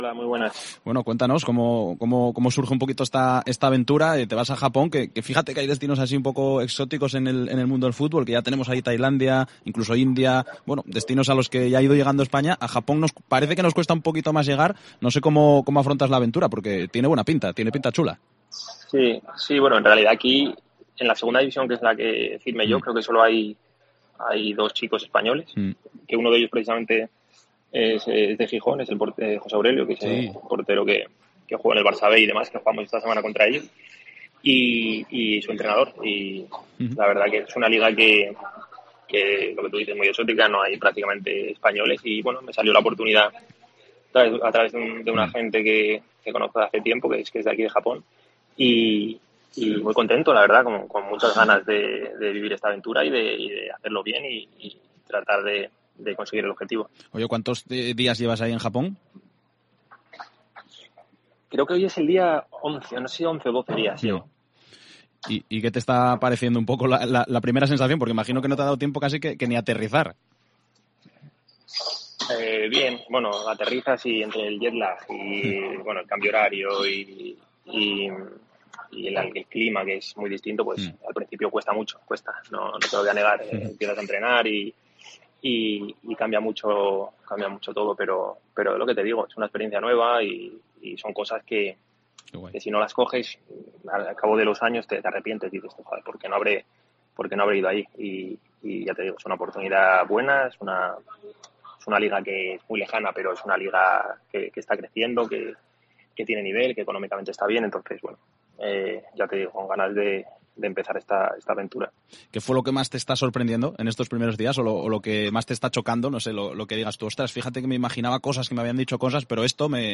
Desde la isla de Okinawa atiende la llamada de Deportes COPE Asturias, nos cuenta como afronta la nueva experiencia y también se despide de Pablo Pérez, con el que compartió vestuario en el Sporting.